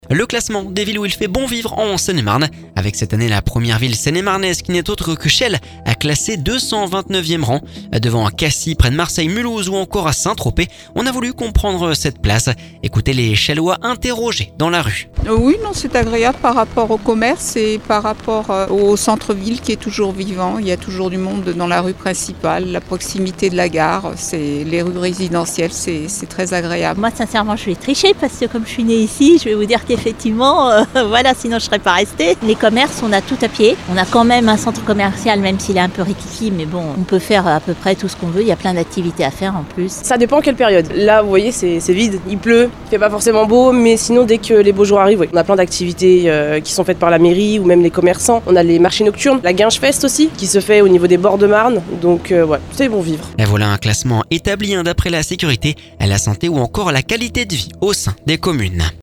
Ecoutez les Chellois interrogés dans la rue…